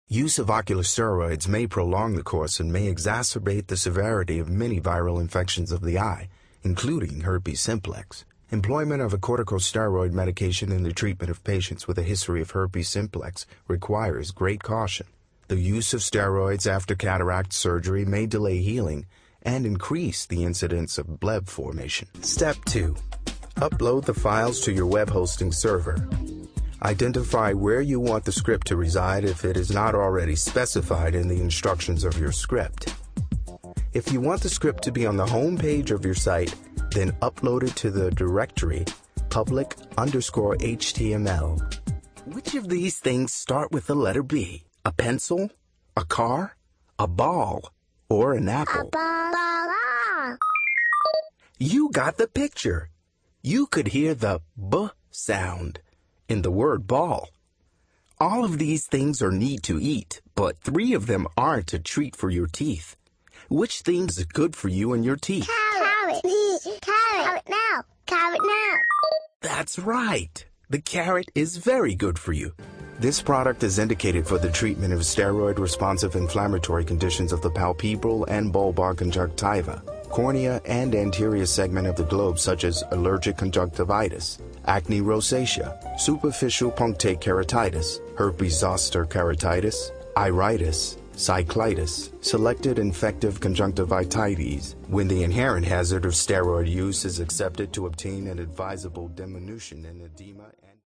mid-atlantic
middle west
Sprechprobe: eLearning (Muttersprache):